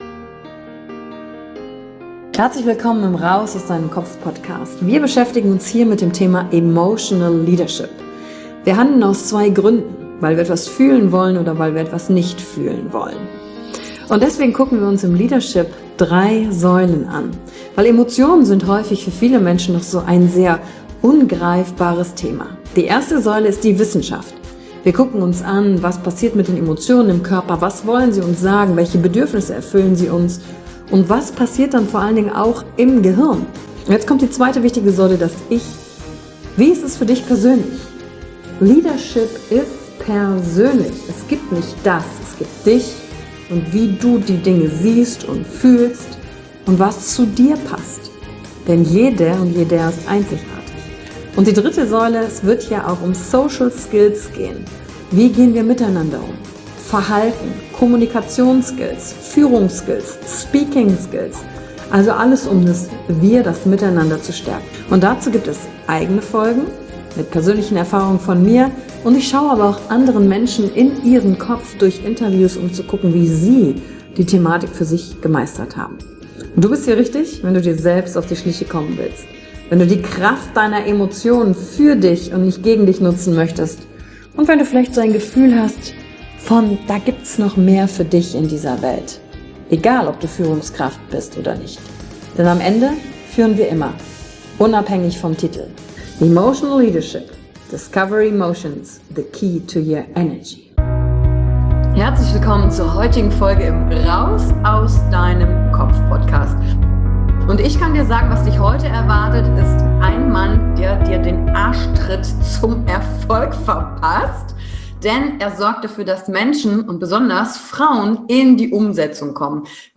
Heute habe ich wieder ein Interview für dich.